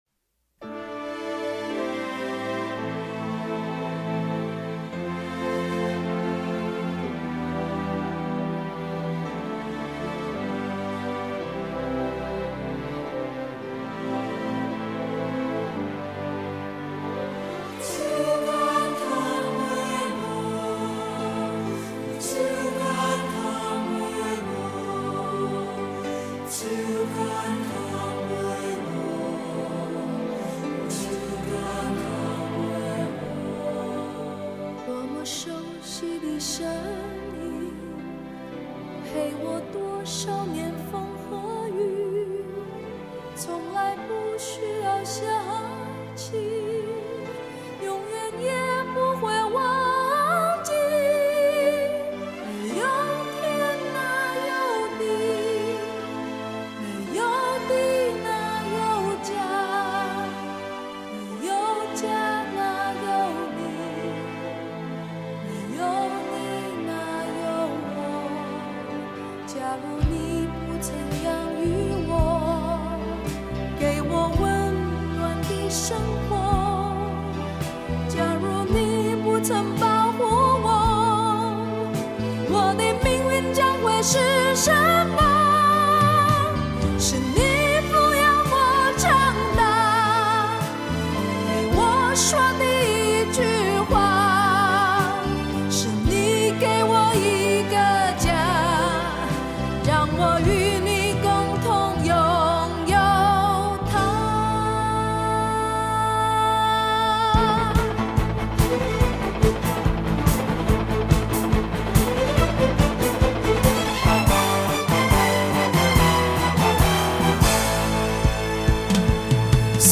试听的是较低品质